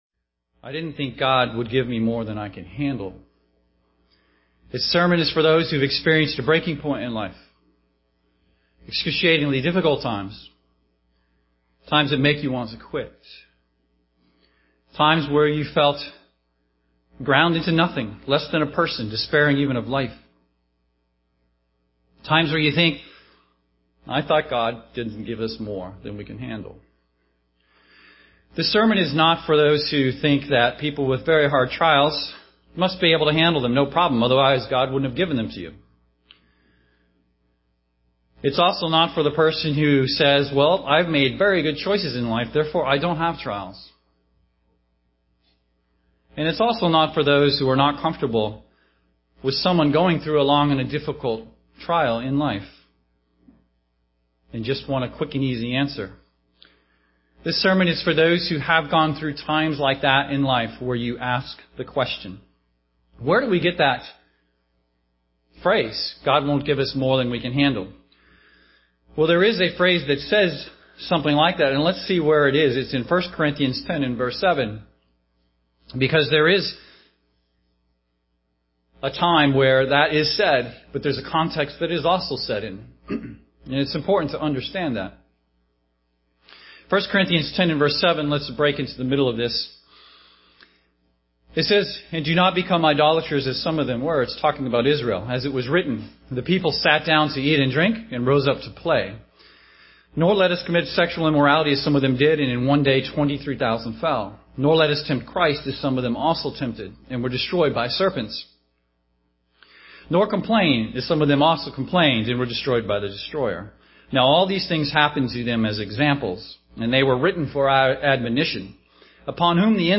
Print Trials, Suffering UCG Sermon Studying the bible?
Given in Seattle, WA